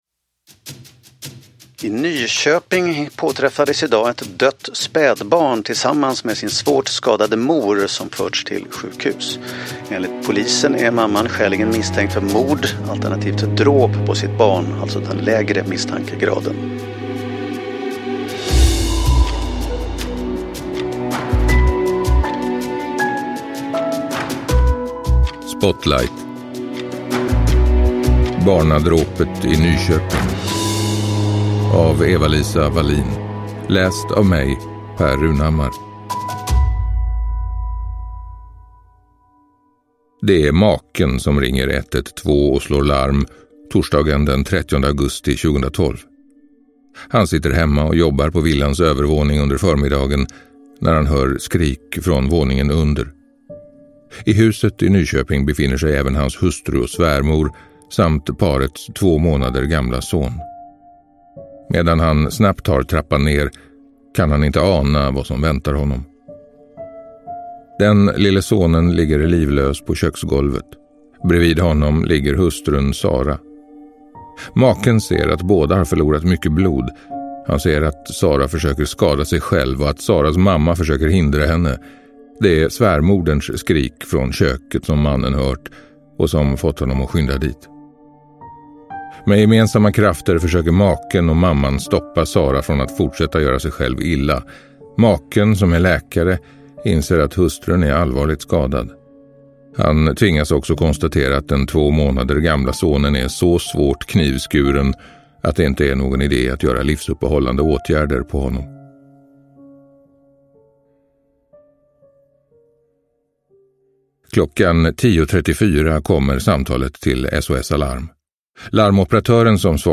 Barnadråpet i Nyköping – Ljudbok – Laddas ner